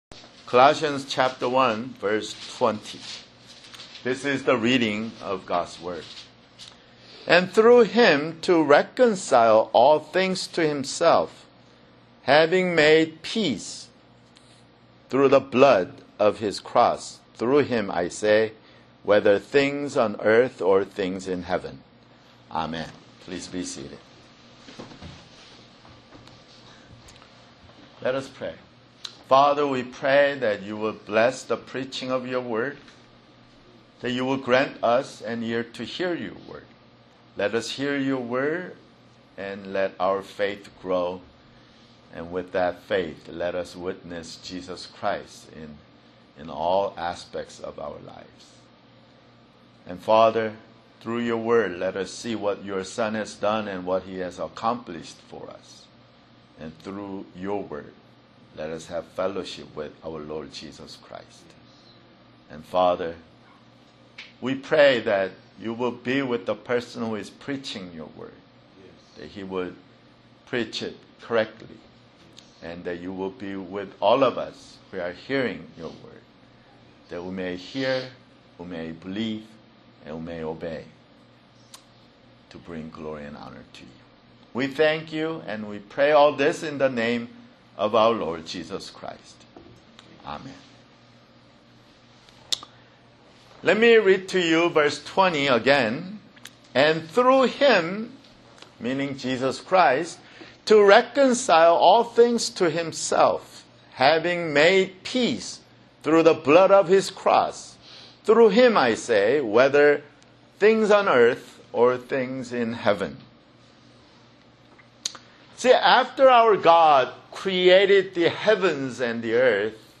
Sunday, May 27, 2018 [Sermon] Colossians (31) Colossians 1:20 (31)